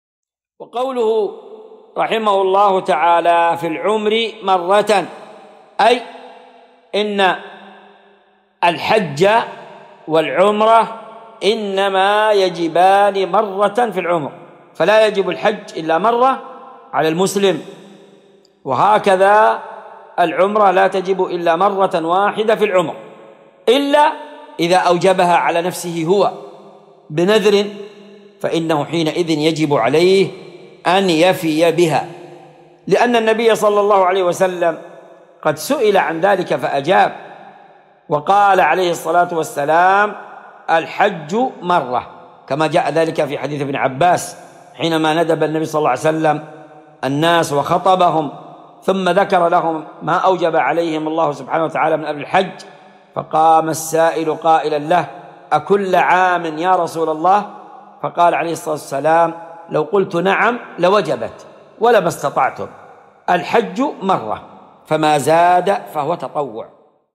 مقتطف من شرح كتاب الحج من عمدة الفقه الشريط الأول .